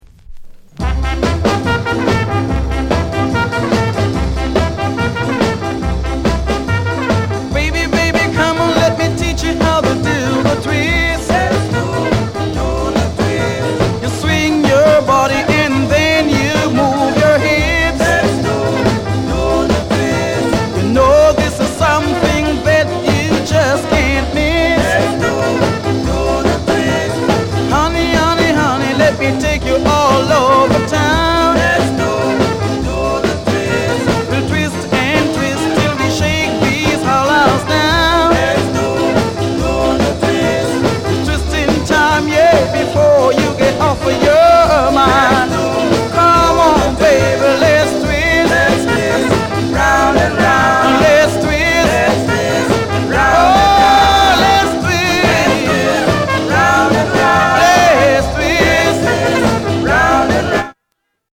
SOUND CONDITION A SIDE EX-
PRE SKA